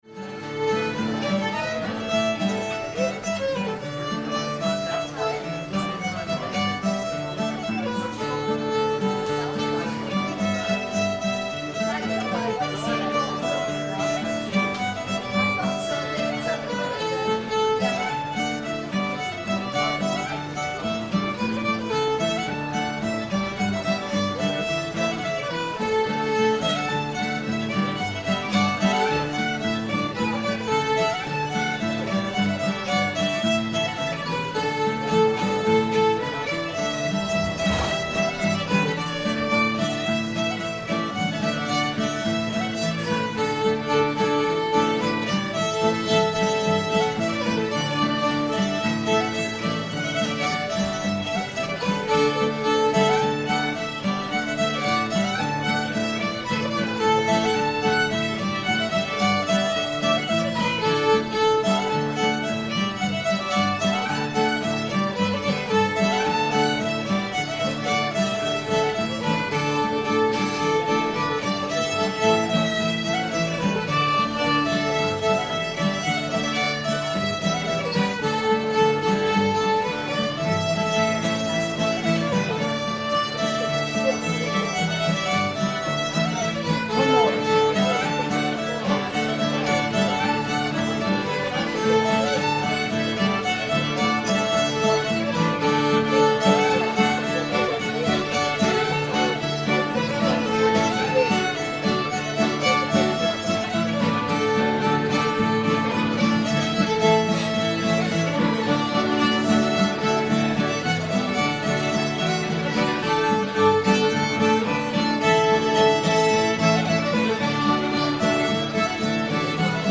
going across the sea [D]